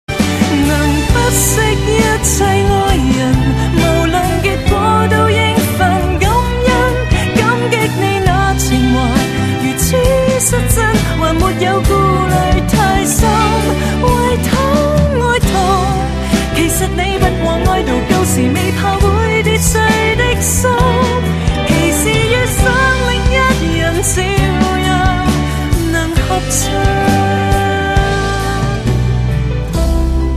华语歌曲
粤语